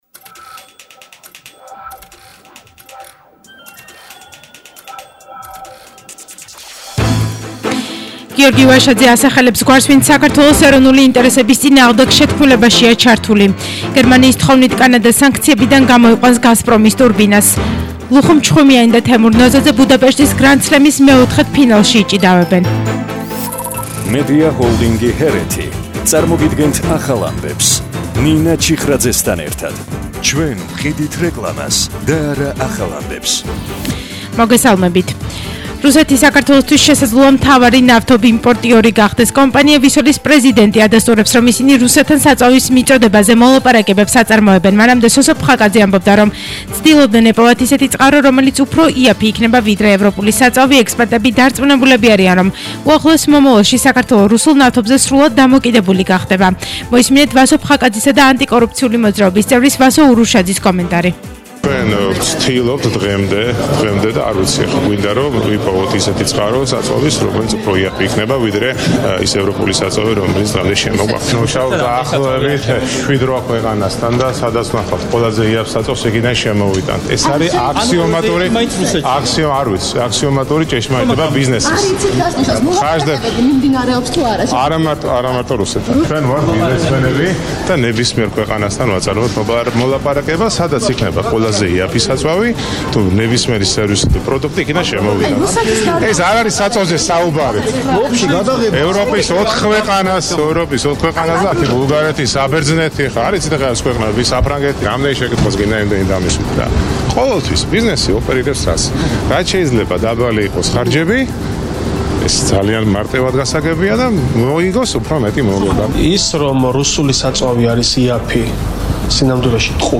ახალი ამბები 16:00 საათზე – 08/07/22